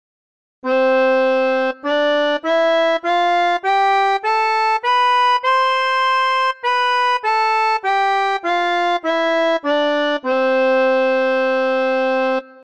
021：リードオルガン（Reed organ）
ＧＭ音源プログラムチェンジの２１番は、リードオルガン（Reed organ）の音色です。
このような仕組みなので、パイプオルガンや電気オルガンに比べて弱い音しか出ません。
「素朴な」感じを出したいとき、もしくはリード・オルガンが使われていた昭和時代の小学校の雰囲気を醸し出したいとき、この音色を使うとよいです。